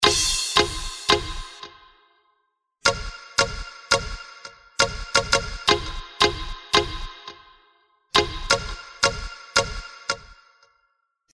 干式吉他循环
标签： 85 bpm Hip Hop Loops Guitar Electric Loops 1.90 MB wav Key : Unknown
声道立体声